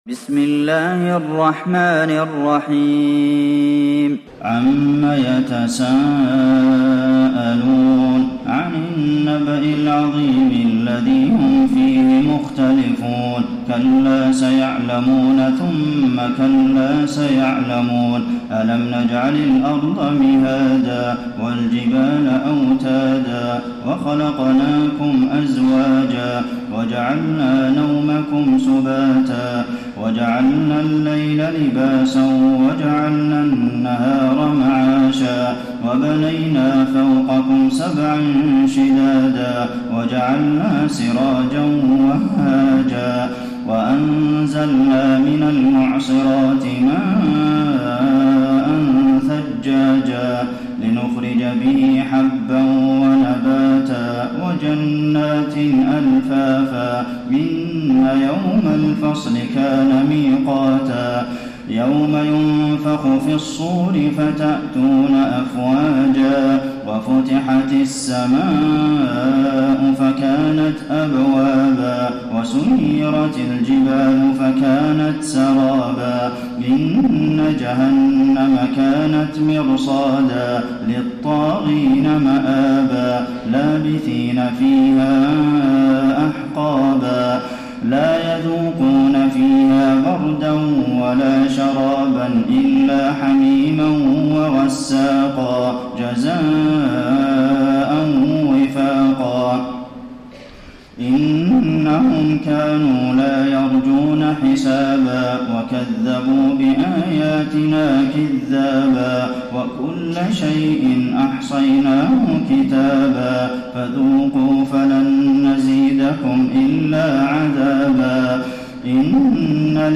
تراويح ليلة 29 رمضان 1433هـ من سورة النبأ الى البلد Taraweeh 29 st night Ramadan 1433H from Surah An-Naba to Al-Balad > تراويح الحرم النبوي عام 1433 🕌 > التراويح - تلاوات الحرمين